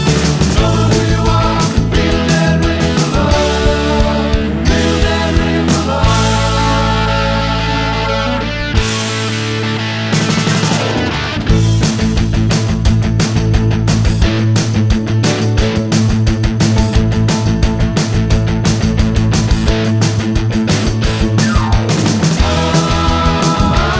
Two Semitones Down Rock 4:15 Buy £1.50